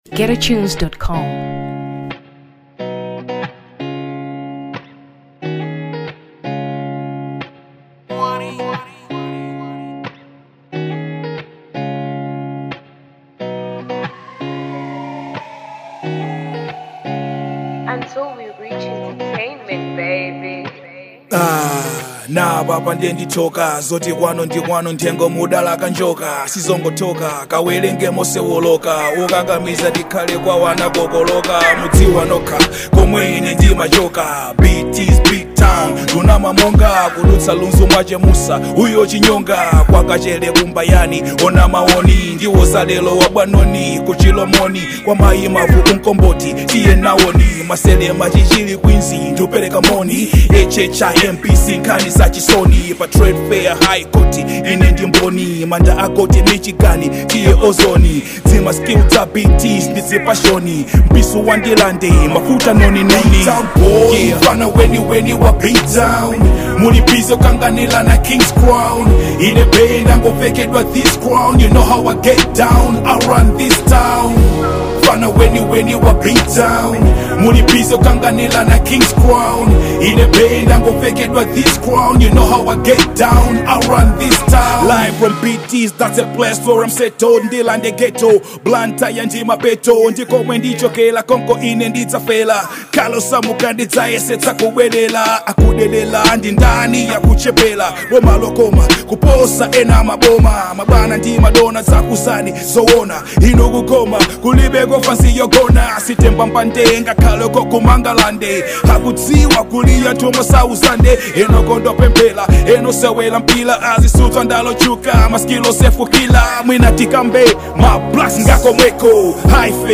Hip Hop 2023 Malawi